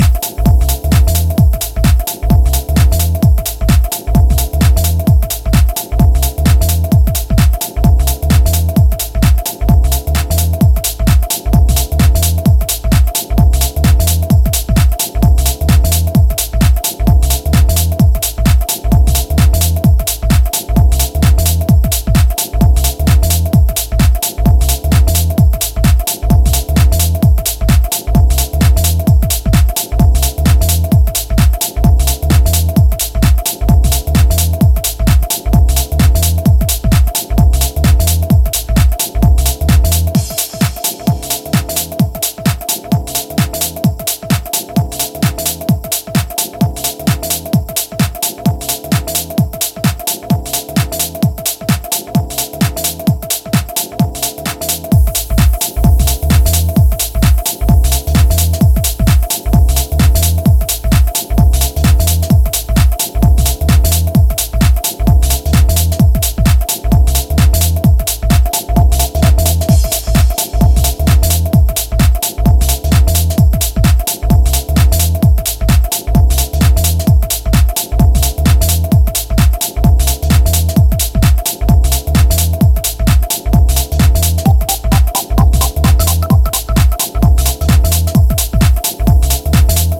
four-to-the-floor club music